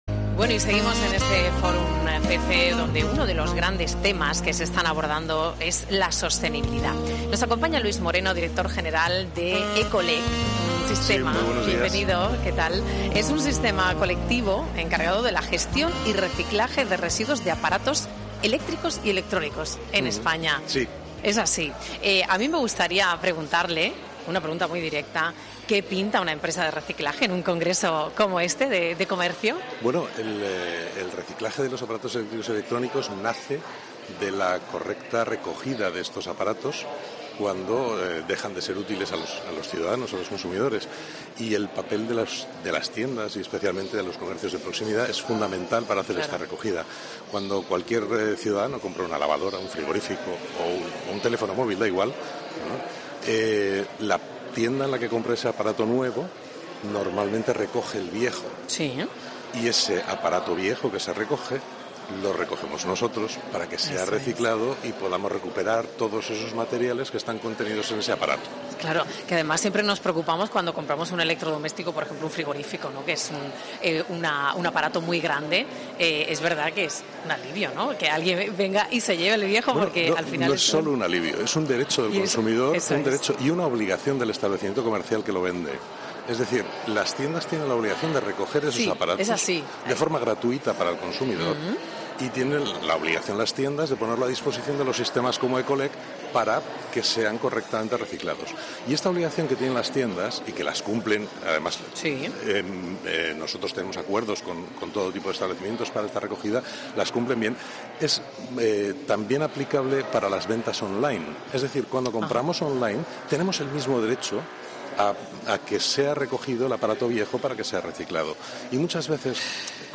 durante su intervención en el programa 'Herrera en COPE Más Sevilla' en el marco de la II edición del Fórum FÉCE, organizado por la Federación Española de Comercio de Electrodomésticos en Sevilla.